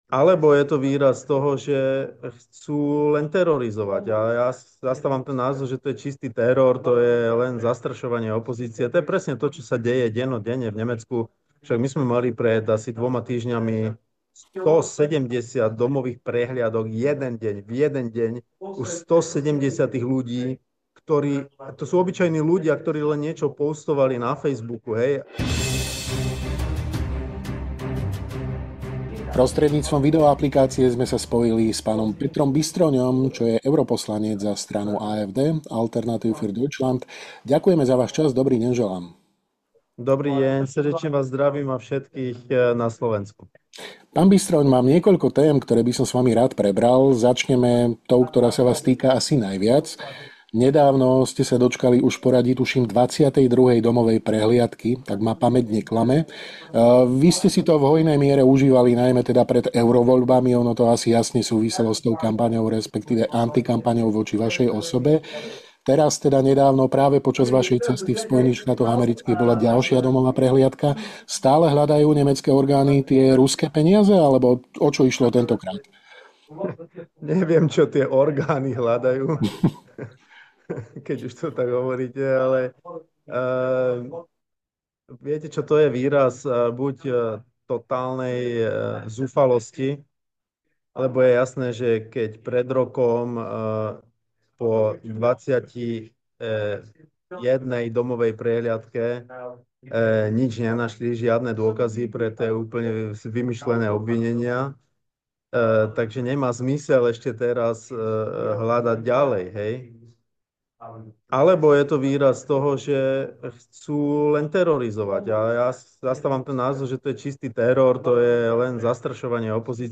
Dozviete sa vo videorozhovore s europoslancom a bavorským šéfom AfD, Petrom Bystroňom.
Viac v rozhovore.